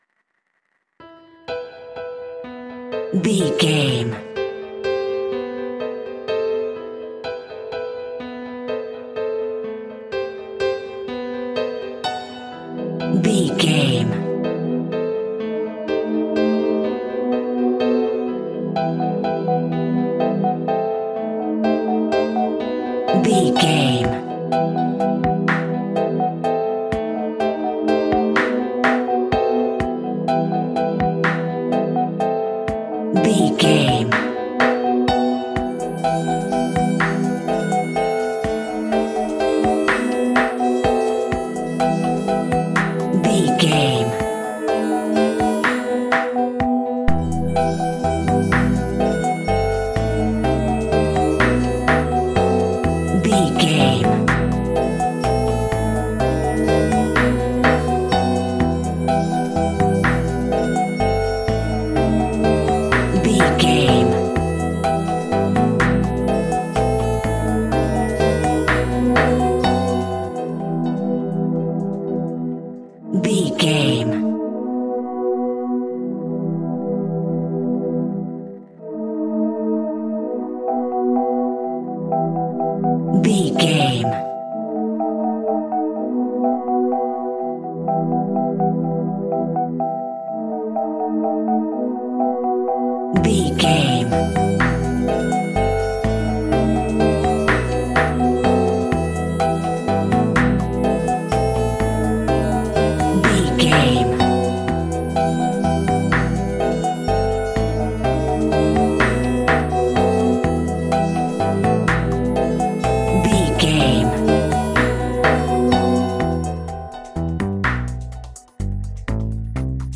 In-crescendo
Thriller
Aeolian/Minor
tension
ominous
eerie
strings
brass
percussion
violin
cello
double bass
taiko drums
timpani